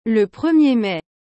le premier maiル プルミエ ンメ